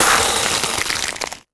Magic_Spells
A=PCM,F=96000,W=32,M=stereo
ice_spell_freeze_frost_03.wav